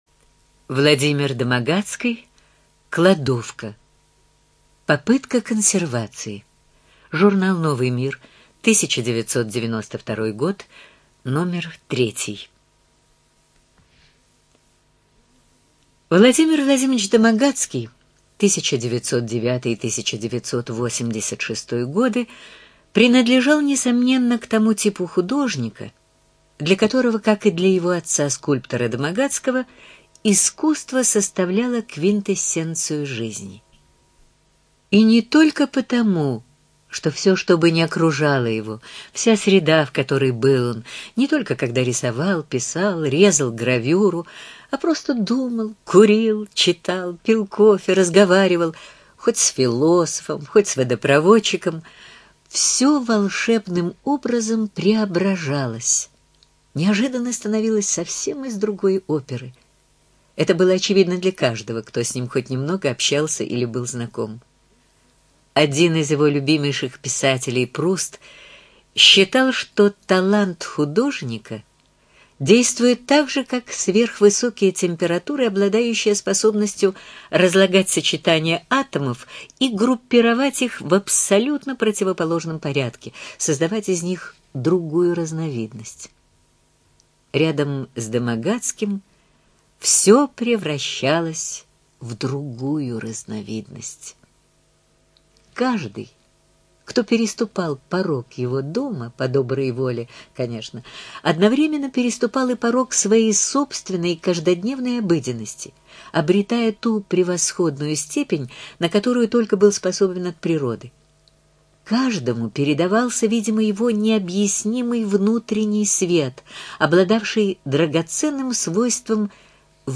Студия звукозаписиЛогосвос